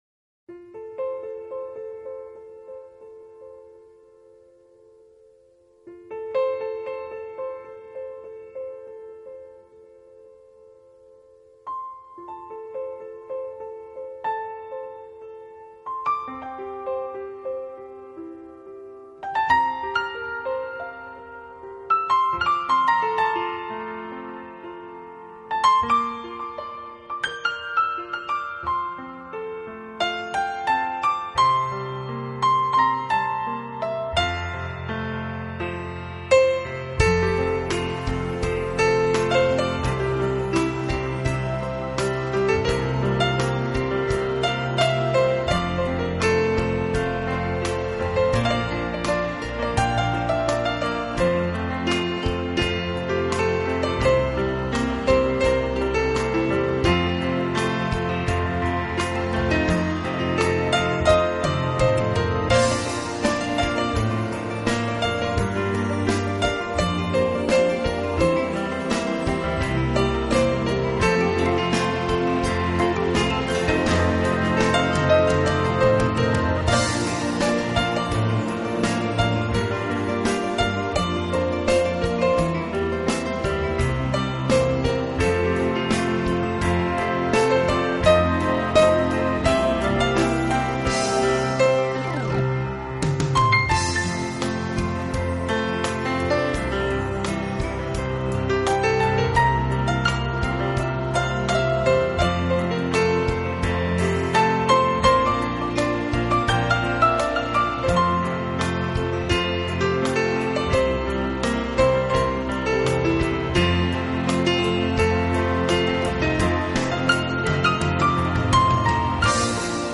读一首首的抒情诗，有象在欣赏一幅幅的风景画……